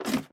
minecraft / sounds / step / ladder1.ogg
ladder1.ogg